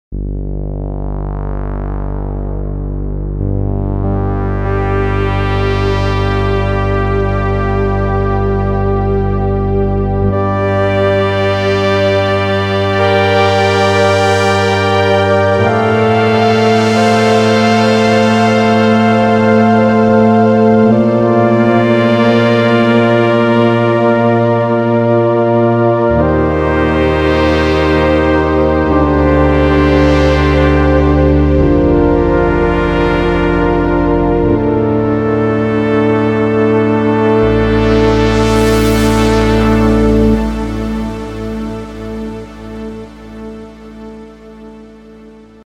Und hier mit dem UHBIE-Filter mit Lowpass-Charakteristik:
Der Sound wirkt voller, in den oberen Mitten und Höhen detailreicher und farbenprächtig schimmernd.